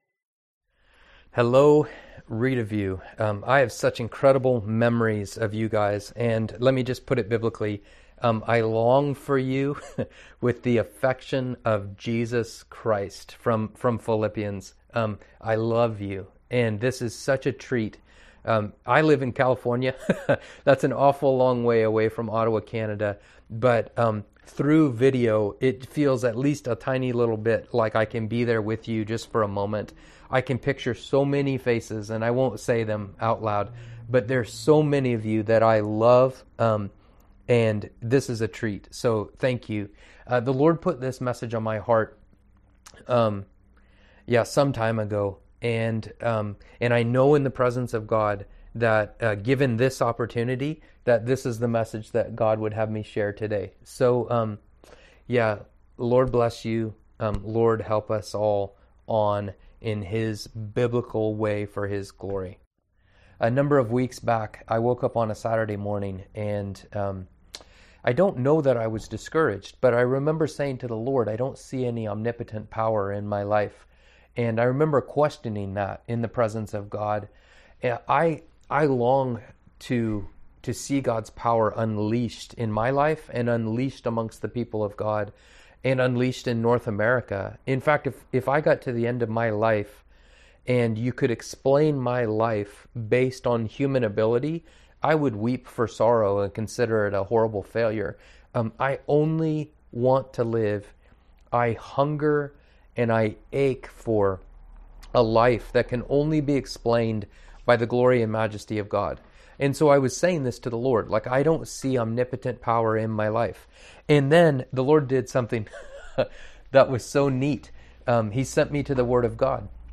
Service Type: Sunday AM Topics: Hope , Intimacy with God , Joy , Patience